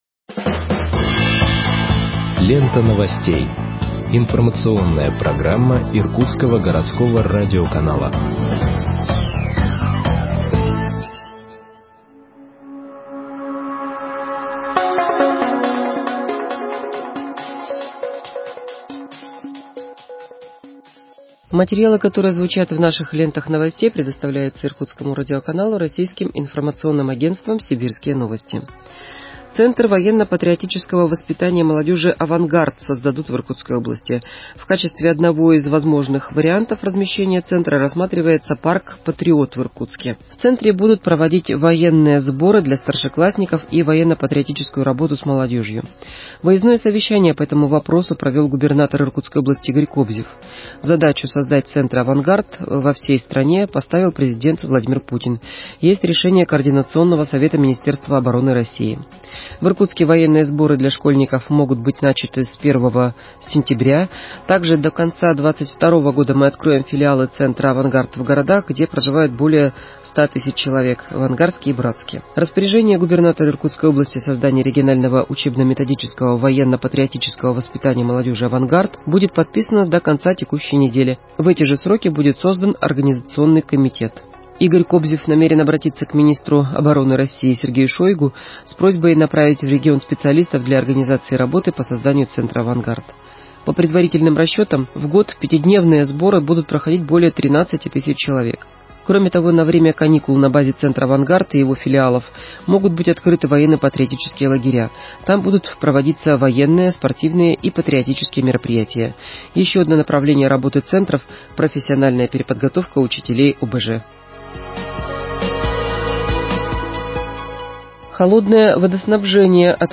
Выпуск новостей в подкастах газеты Иркутск от 19.05.2021 № 1